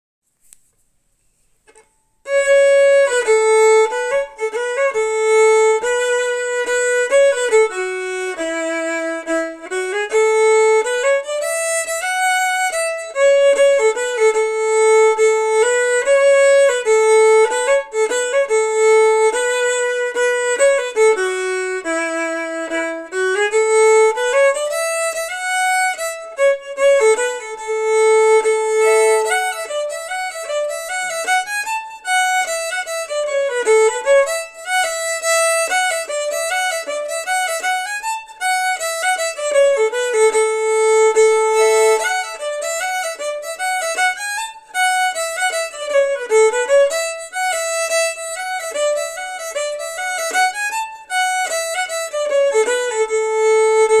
Key: D, G & A (or more?) versions*
Form: March & song, (old-time)
Source: Traditional
Region: USA (Appalachia)